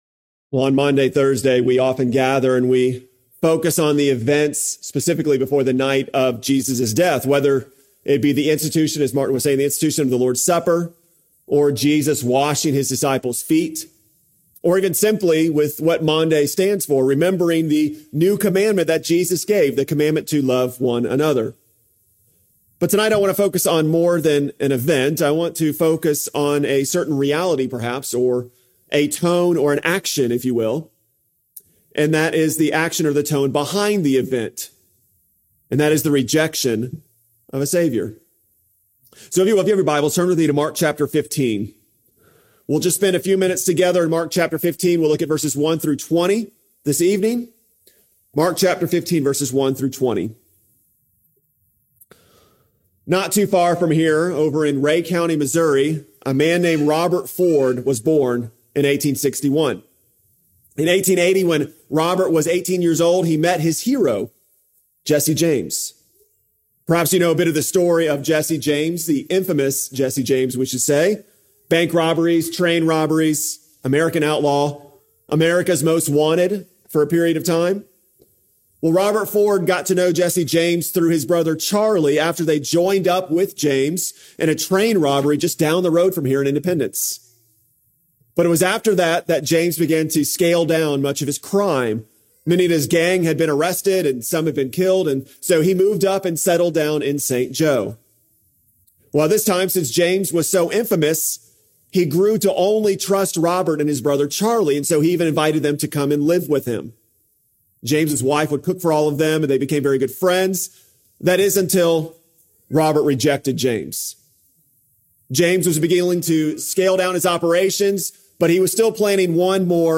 Maundy Thursday Service